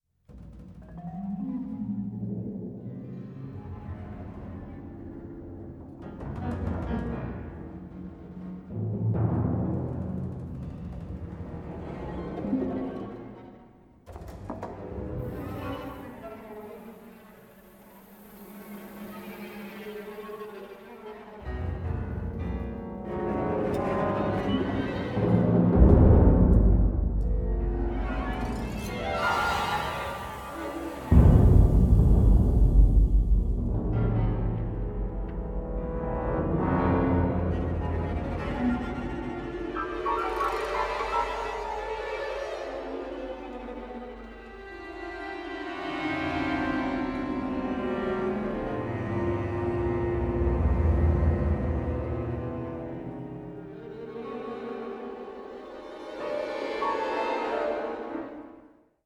Stereo / Surround 5.1 / DTS Surround 5.1